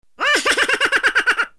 efect - raset de pitic spiridus rau